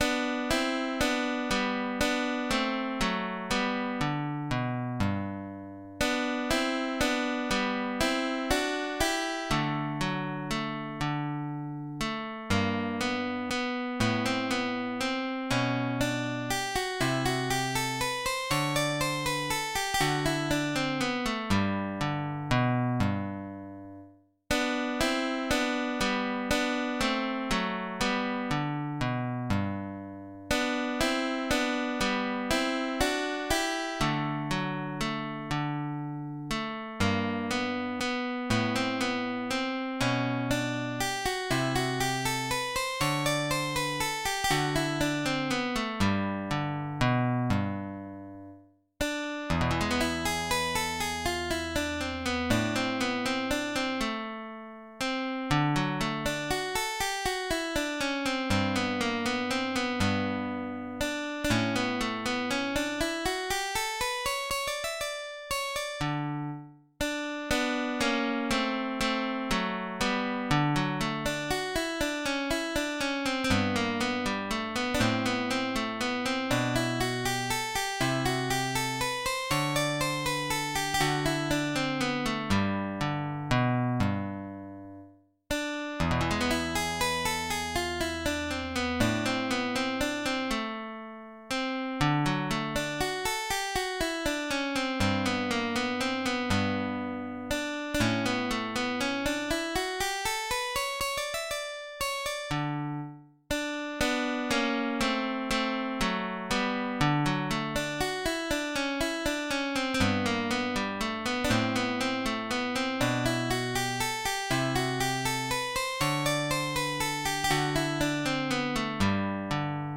in Sol***